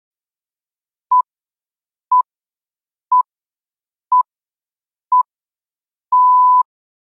Pips (128 kbps).mp3